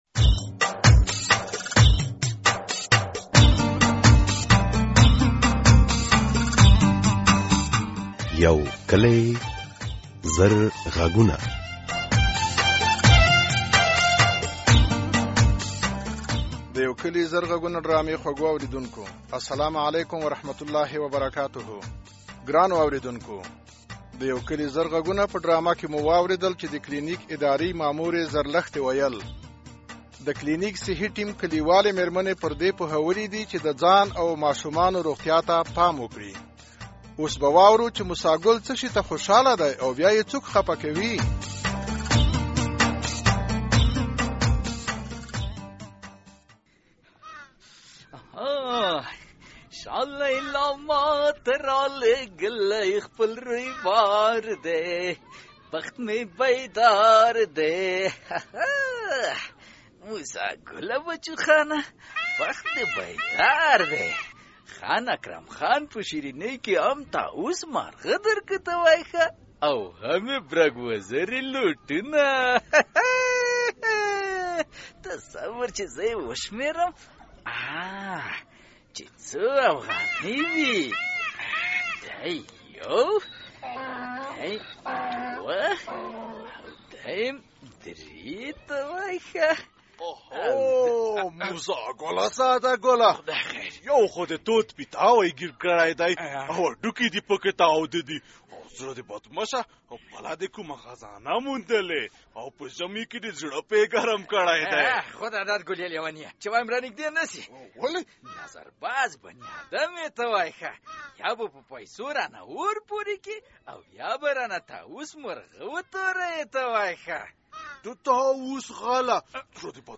د یو کلي زر غږونو ډرامې په ۲۲۳ برخه کې د ماشومانو مهارتونو، وړتیاوو او د پرمختګ ځيني لارو چارو ته اشاره شوې.